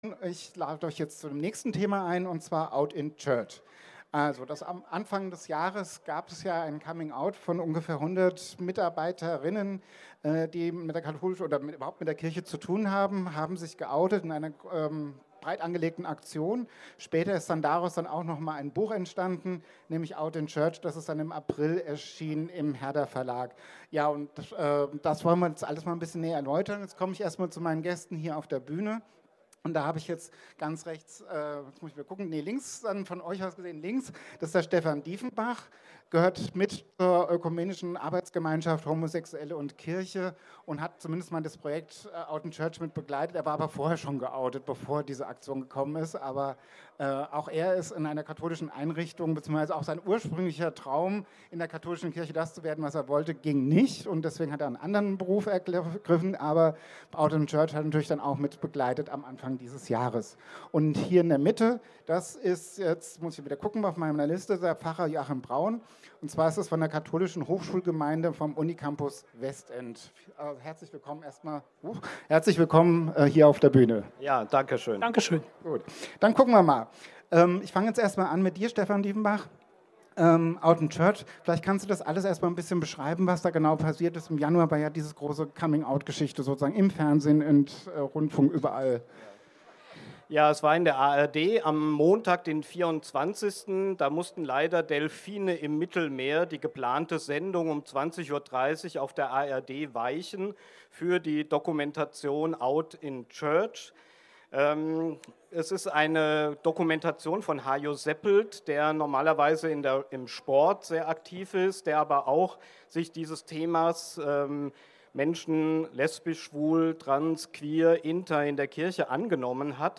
Mitschnitt einer Diskussion auf der Kulturbühne vom CSD 2022